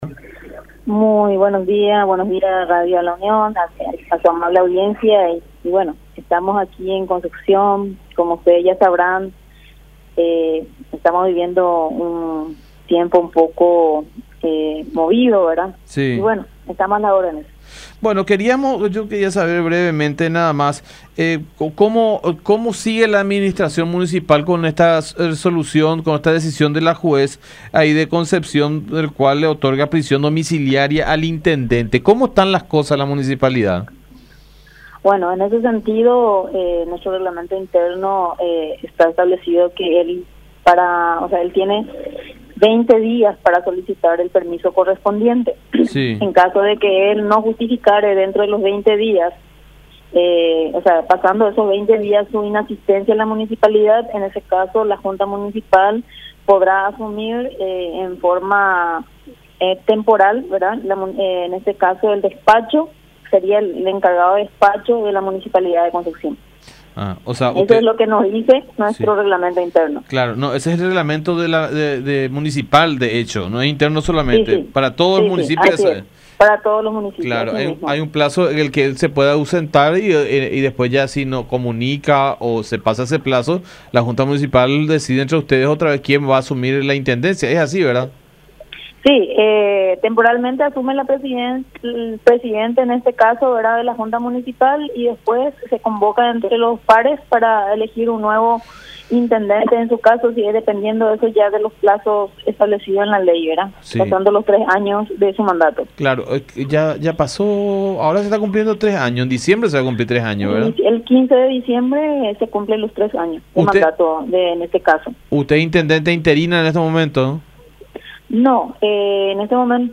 “Eso lo vamos a resolver el próximo martes en la plenaria”, dijo en comunicación con La Unión.